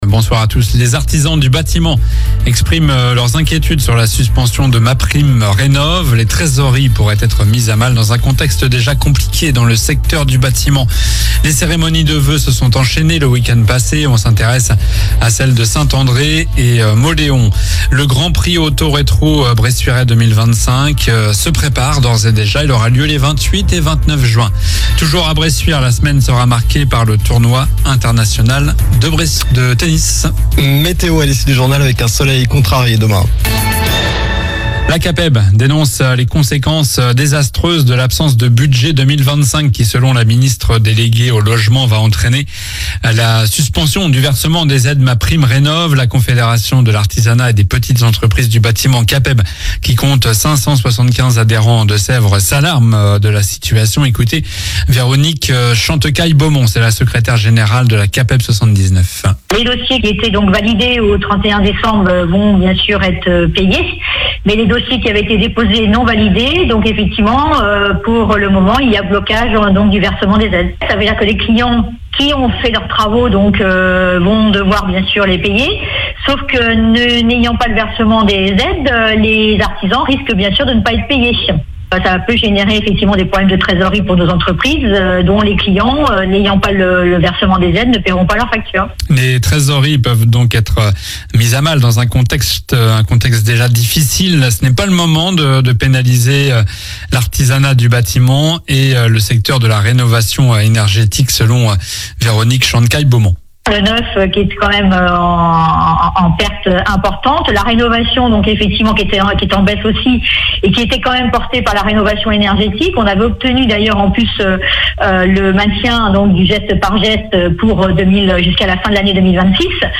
Journal du lundi 13 janvier (soir)